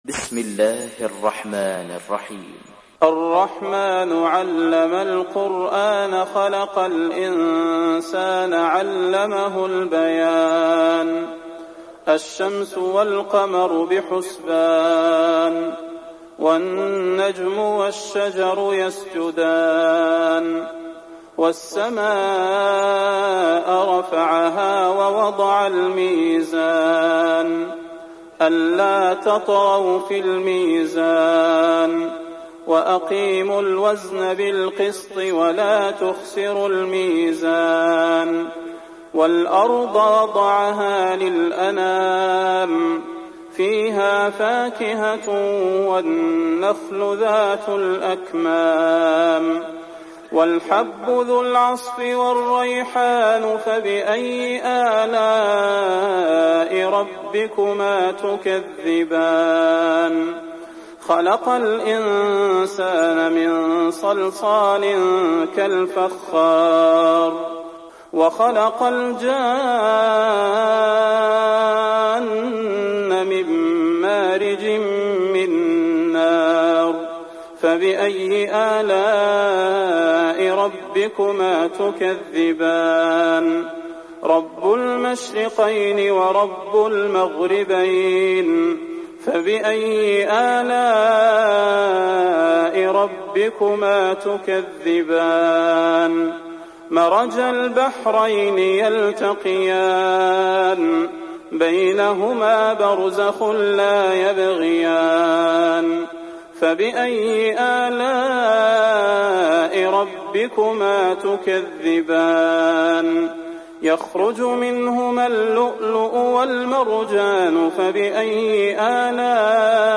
সূরা আর-রাহমান ডাউনলোড mp3 Salah Al Budair উপন্যাস Hafs থেকে Asim, ডাউনলোড করুন এবং কুরআন শুনুন mp3 সম্পূর্ণ সরাসরি লিঙ্ক